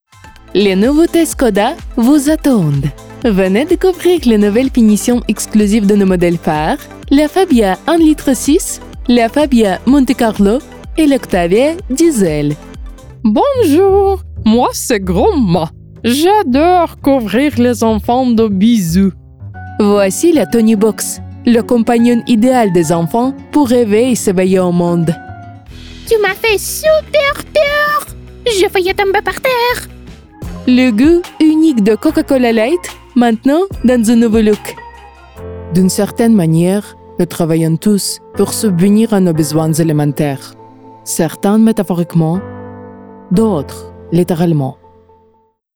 Native Ukrainian & Russian Voice Artist | English & French with Slavic Accent
French Multi-Style Reel (Slavic accent)
My delivery ranges from calm, sophisticated narration and warm commercial tones to expressive, high-energy storytelling.